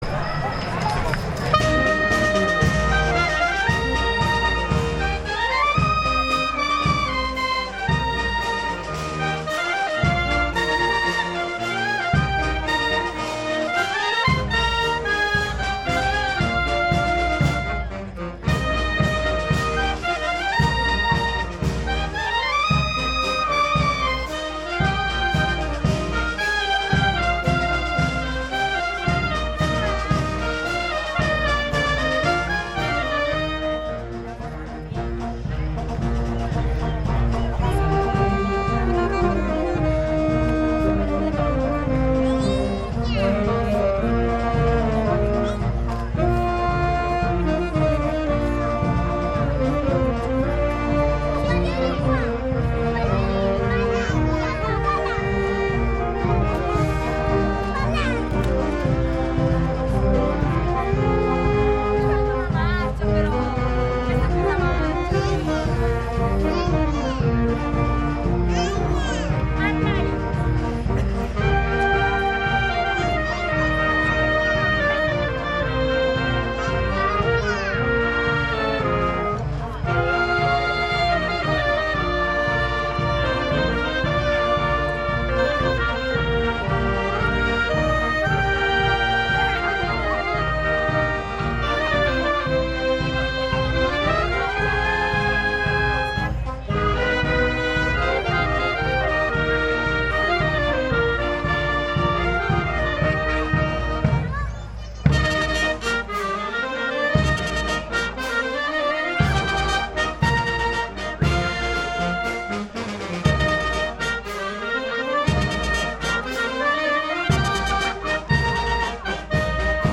Gambatesa: Raduno Bandistico Del 14 Agosto. Resoconto
Delle bande è stata presa la foto, un breve video perché per ragioni tecniche i pezzi interi non sarebbe stato possibile caricarli e l’audio stereo delle esecuzioni, tutto gratuitamente scaricabile da chi ne vorrà serbare ricordo.